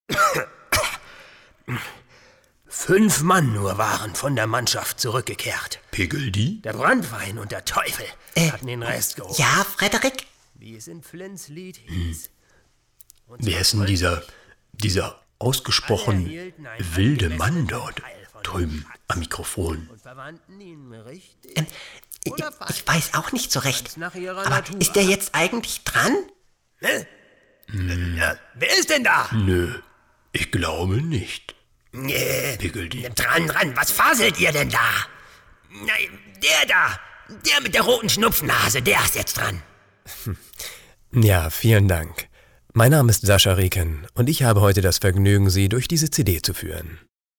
Dialekt: Norddeutsch
Hörspiel - Comedy - Stimmen
POP_Schauspiel_Piggeldy_Mix-2025.mp3